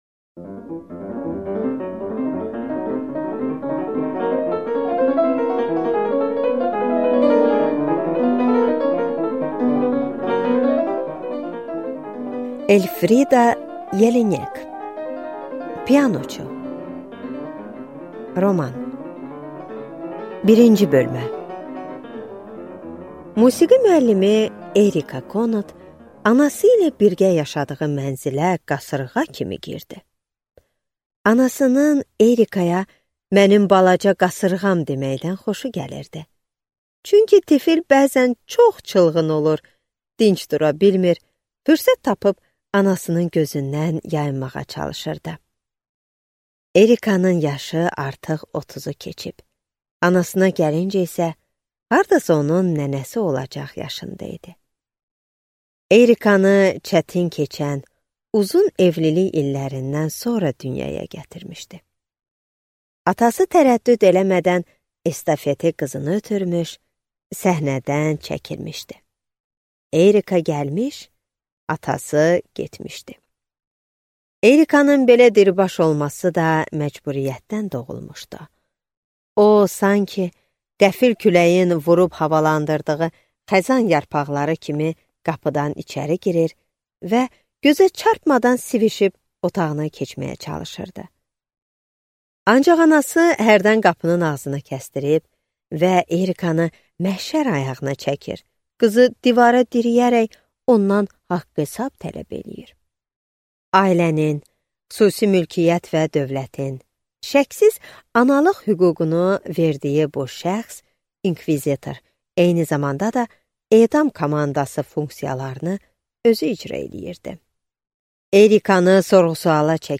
Аудиокнига Pianoçu | Библиотека аудиокниг
Прослушать и бесплатно скачать фрагмент аудиокниги